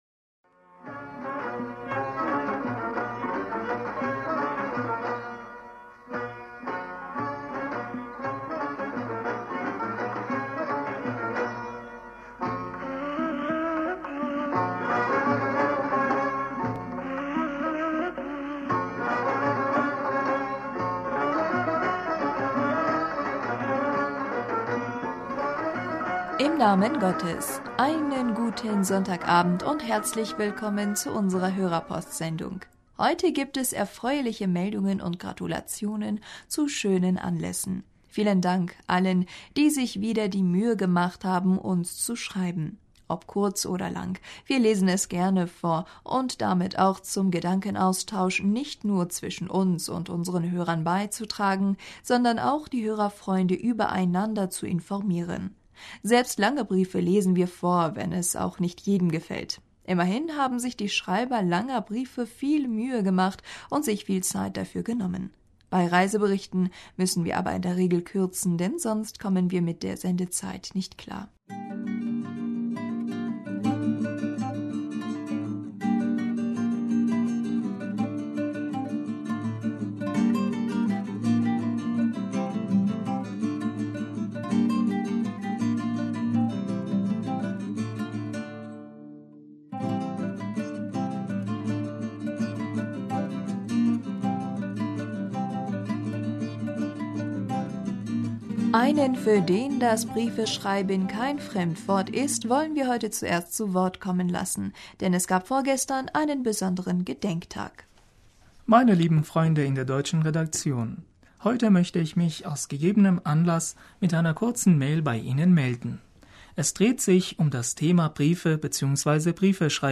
Hörerpostsendung am 03.September 2017 - Bismillaher rahmaner rahim - Einen guten Sonntagabend und herzlich willkommen zu unserer Hörerpostsendung....
Ob kurz oder lang, wir lesen es gerne vor, um damit auch zum Gedankenaustausch nicht nur zwischen uns und unseren Hörern beizutragen, sondern auch die Hörerfreunde übereinander zu informieren.